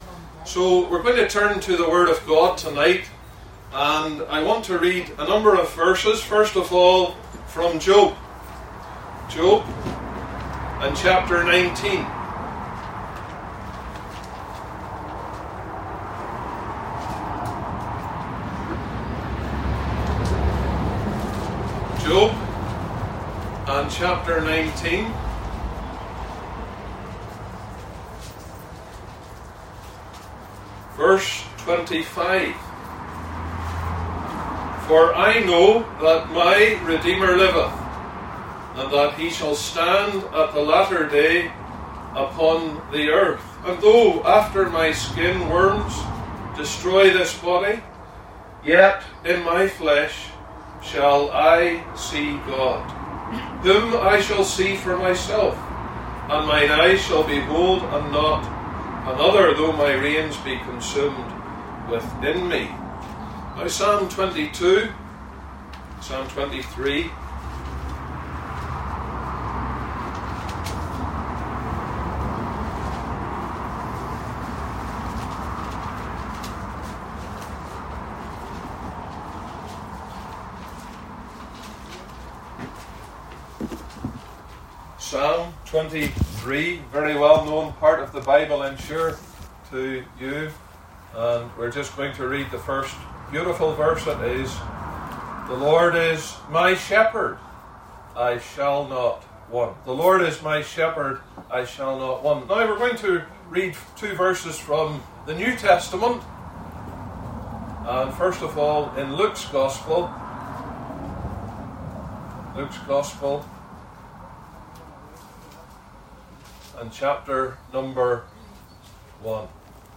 2025 Gospel Tent